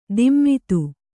♪ dimmitu